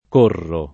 correre [k1rrere] v.; corro [